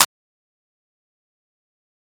Clap (STARGAZING) (1).wav